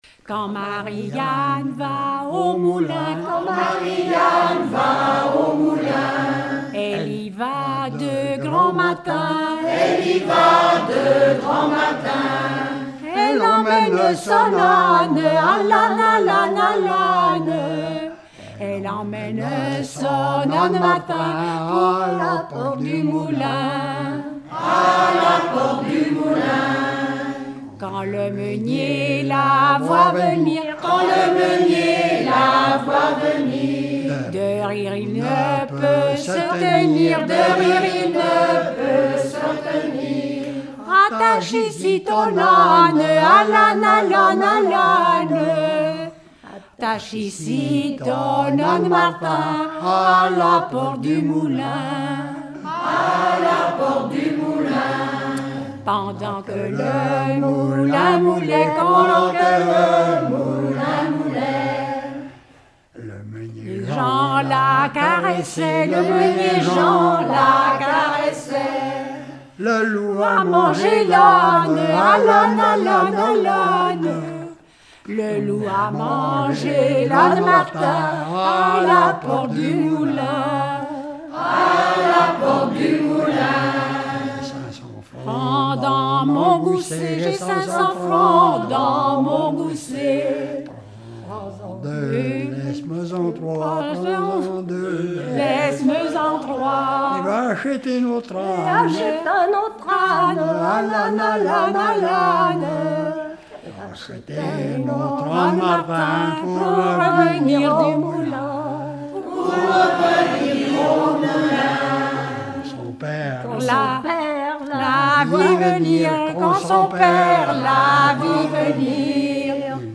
en duo
Genre laisse
Pièce musicale inédite